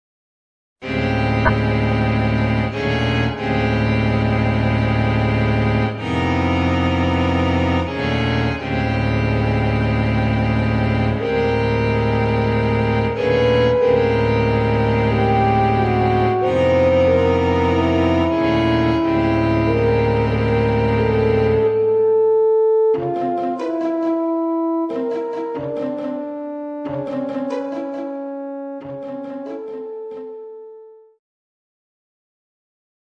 für Horn und Streichertrio